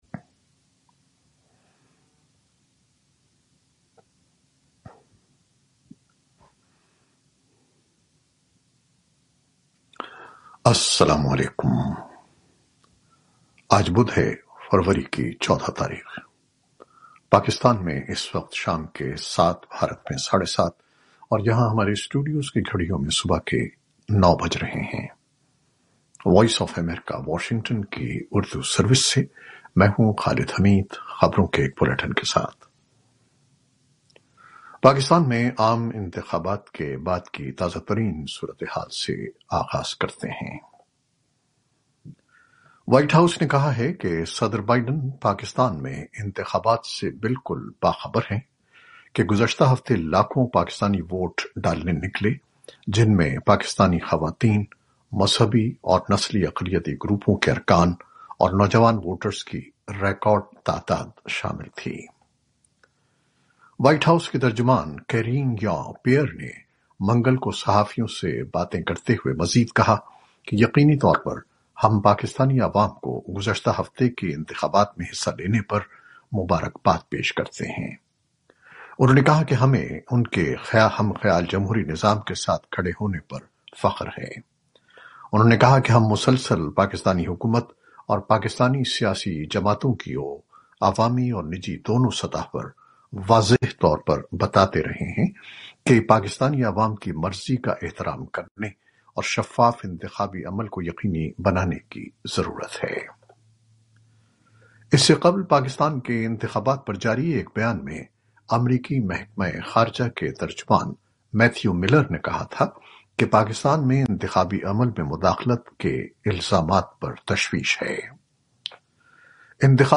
شام سات بجے کی خبریں